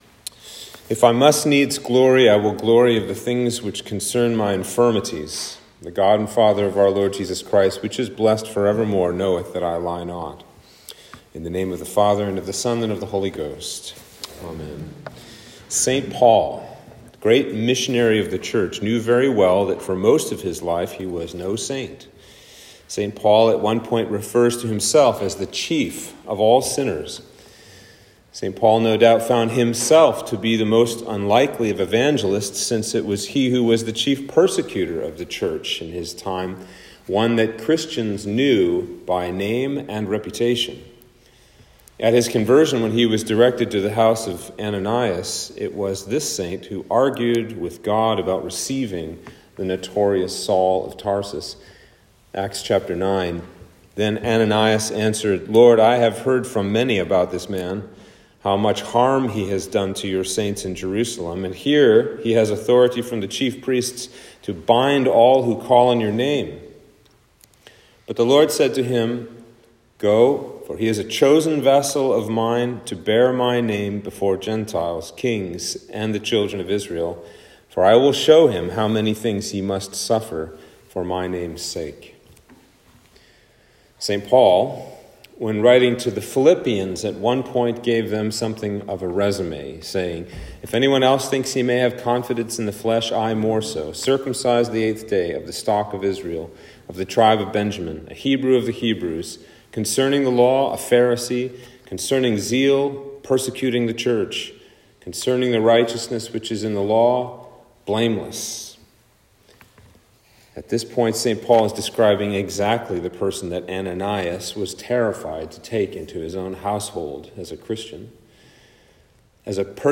Sermon for Sexagesima